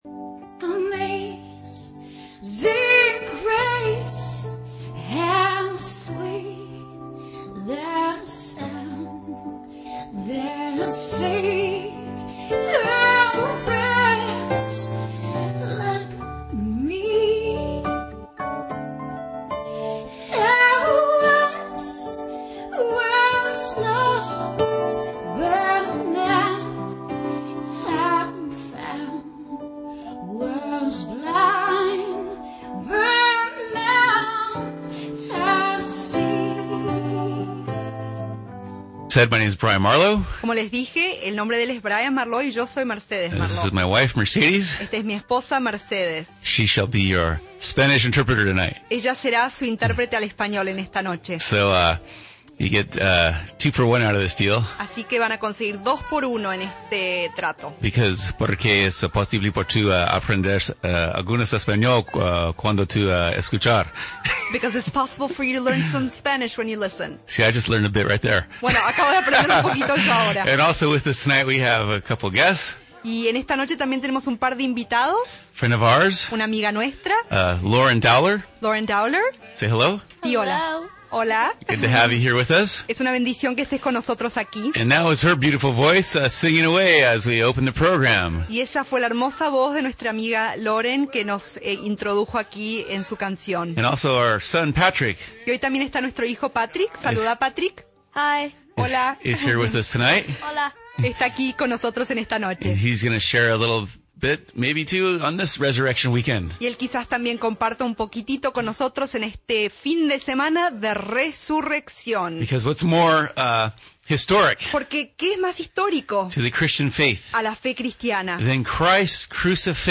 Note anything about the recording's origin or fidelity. (Please excuse our audio levels as a train kept us blocked from getting into the station early enough to get our mic levels just right.)